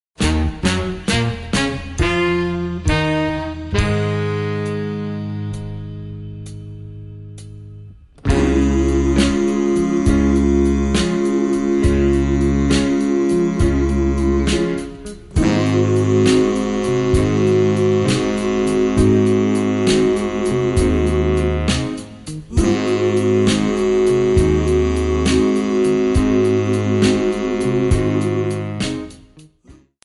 C#
MPEG 1 Layer 3 (Stereo)
Backing track Karaoke
Pop, Oldies, 1960s